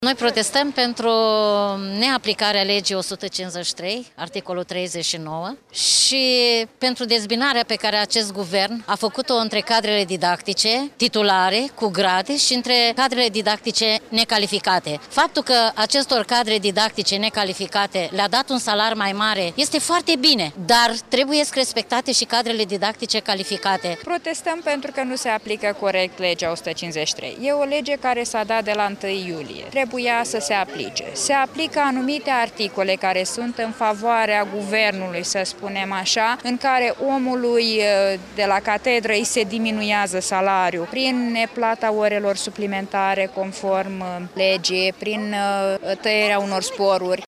13-nov-rdj-17-vox-profesori.mp3